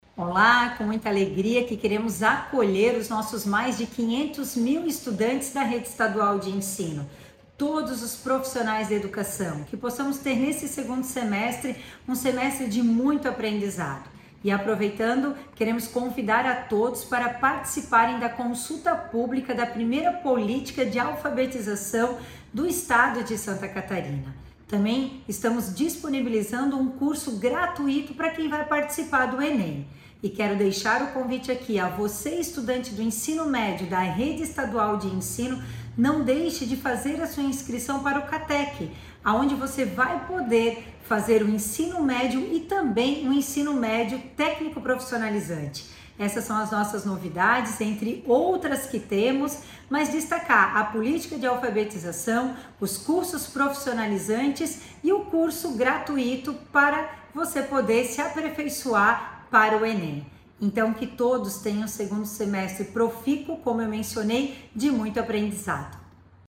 A secretária adjunta, Patrícia Lueders, deu as boas-vindas e falou dos cursos e projetos para o ensino estadual oferecidos pela Secretaria:
SECOM-Sonora-secretaria-adjunta-da-Educacao.mp3